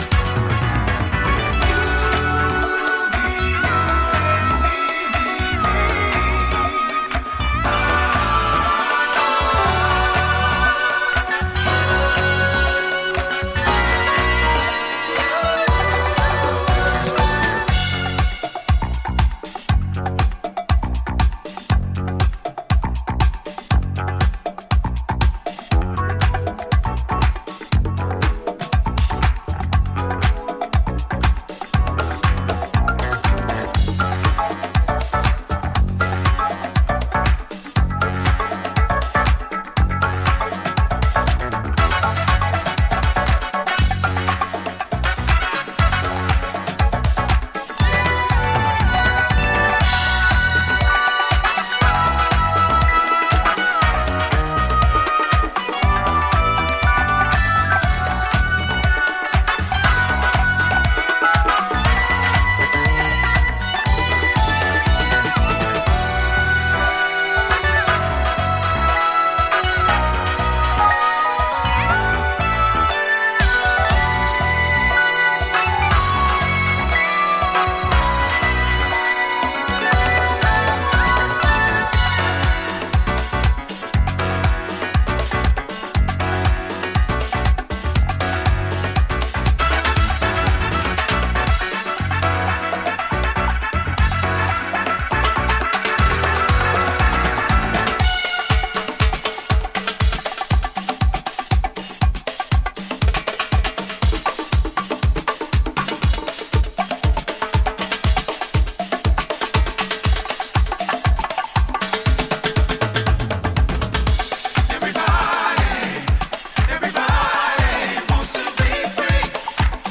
ジャンル(スタイル) HOUSE / DISCO